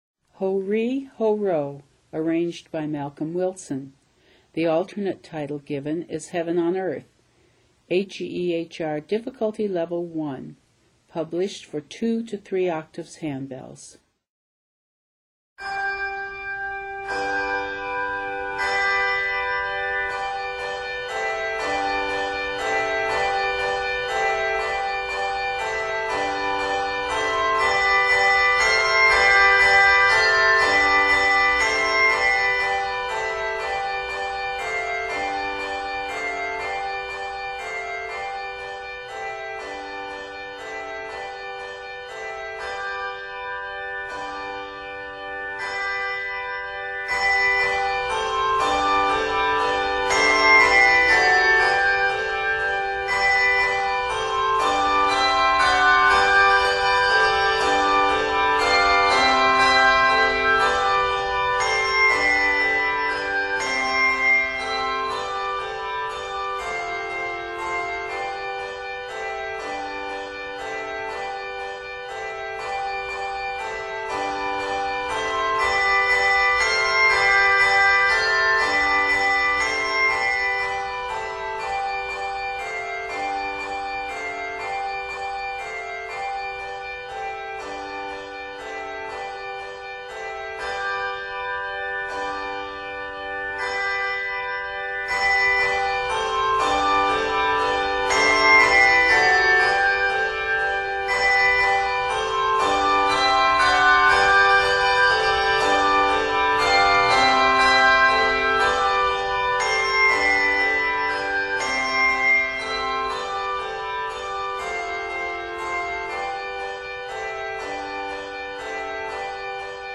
Based on a traditional Gaelic melody
Set in G Major, measures total 53.